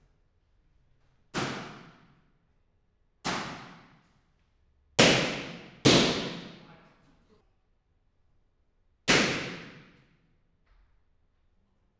Palloncini-XZ.WAV